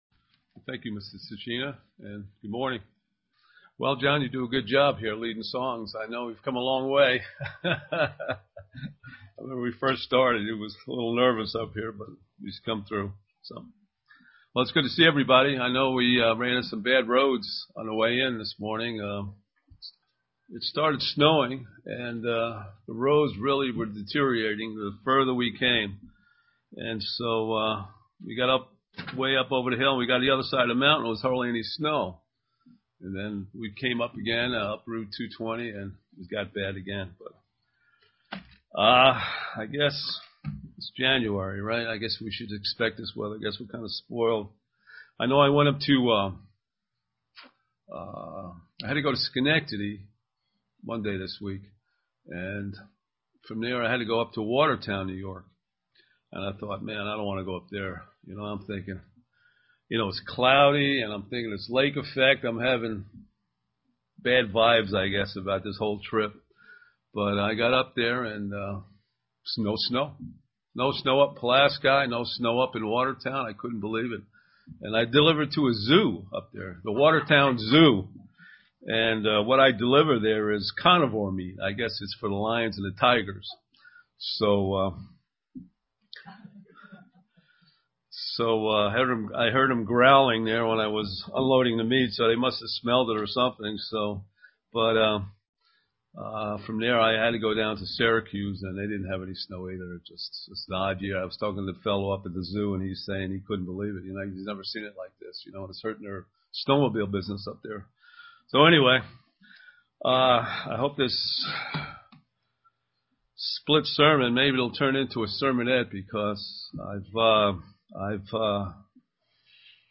Given in Elmira, NY
Print Using Job as our example UCG Sermon